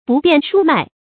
不辯菽麥 注音： ㄅㄨˋ ㄅㄧㄢˋ ㄕㄨ ㄇㄞˋ 讀音讀法： 意思解釋： 分不清哪是豆子，哪是麥子。